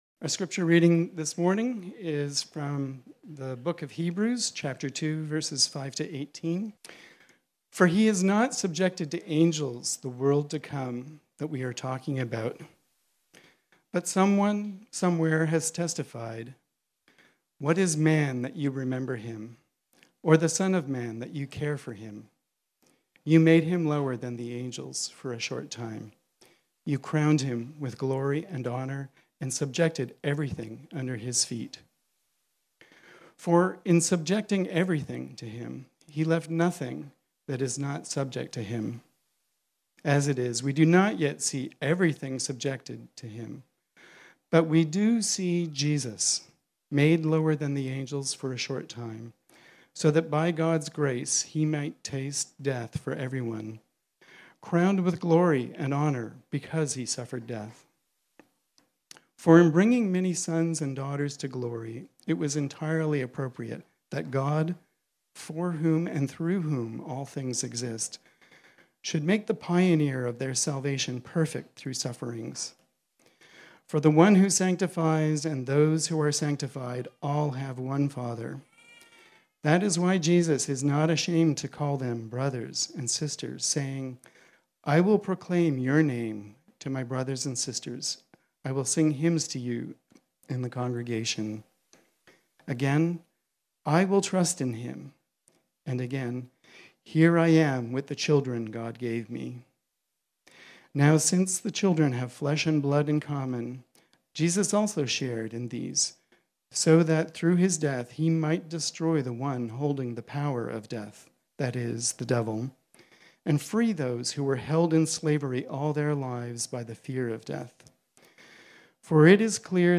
This sermon was originally preached on Sunday, October 2, 2022.